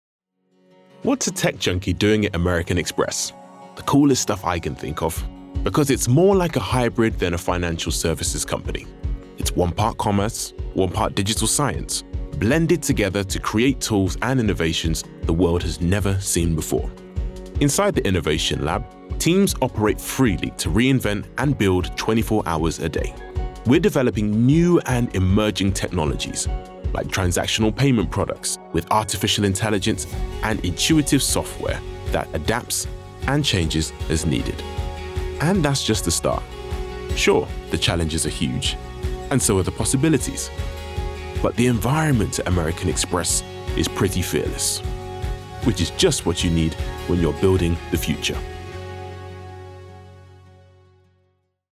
Playing Age: 20-40
Characteristics: Reassuring, Cool, warm, energetic, and friendly
CORPORATE REEL